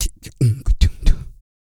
EH REGGAE 3.wav